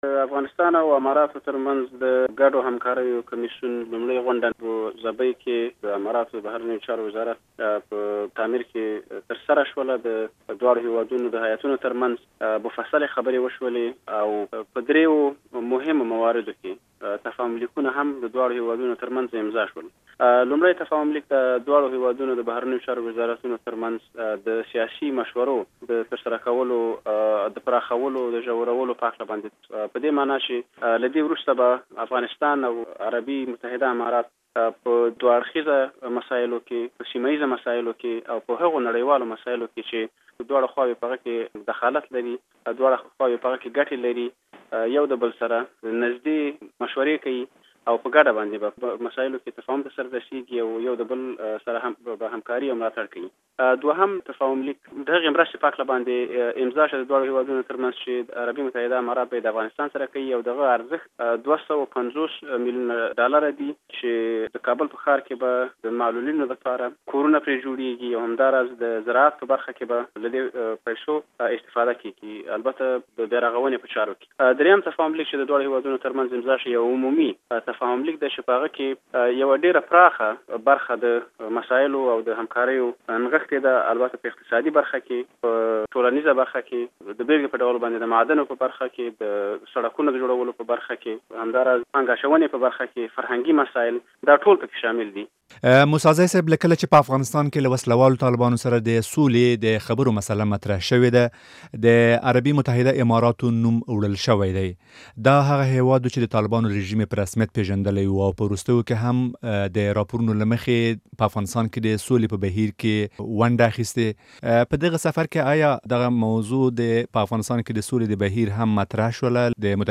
له جانان موسى زي سره مرکه